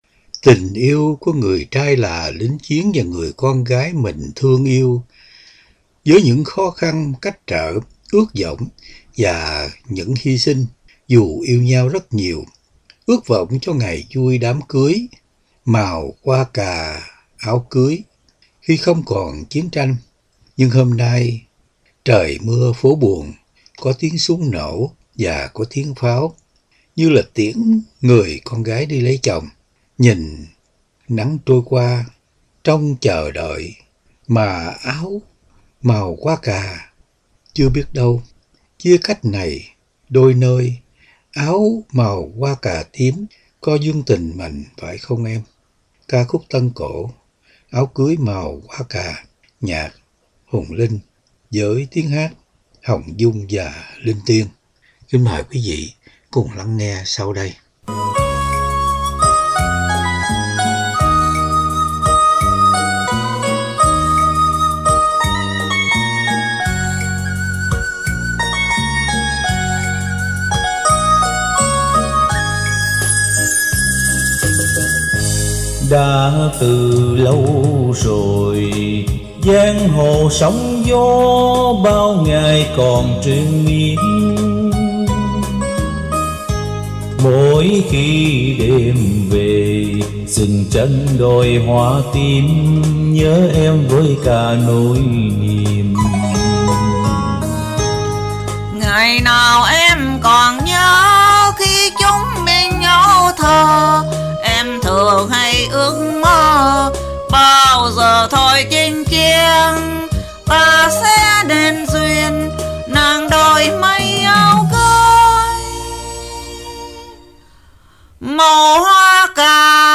Âm Nhạc